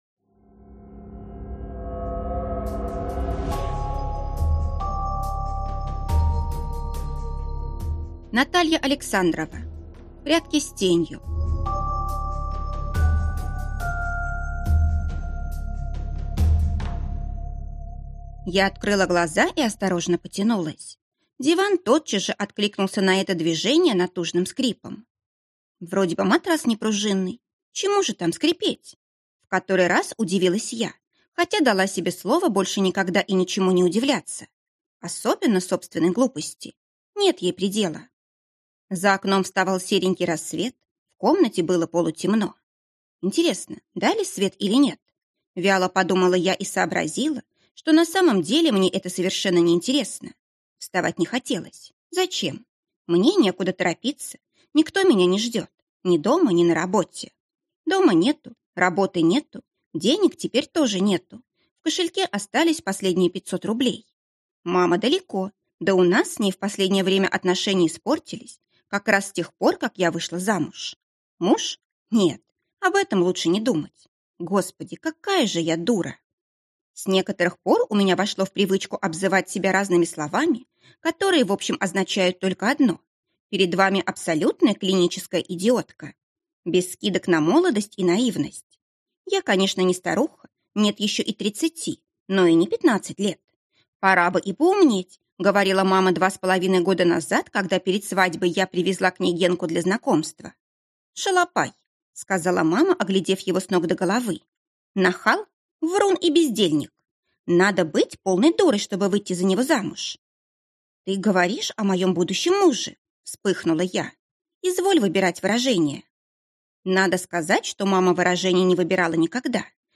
Скачать аудиокнигу Прятки с тенью